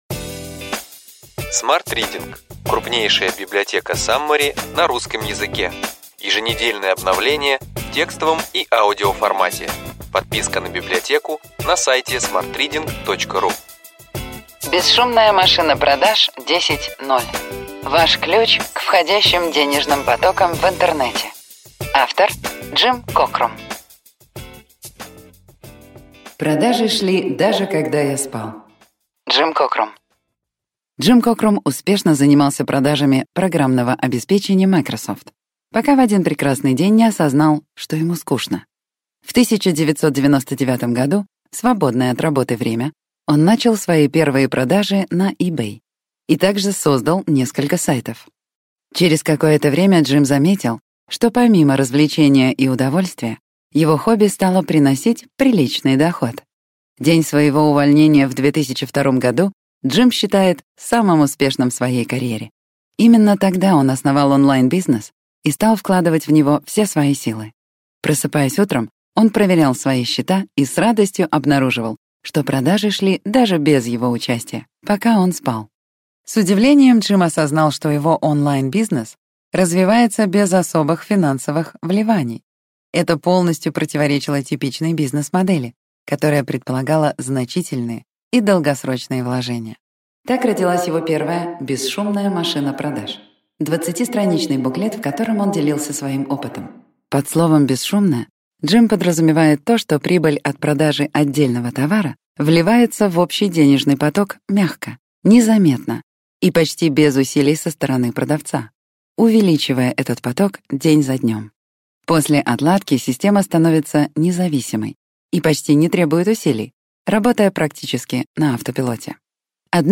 Аудиокнига Ключевые идеи книги: Бесшумная машина продаж 10.0. Ваш ключ к входящим денежным потокам в интернете.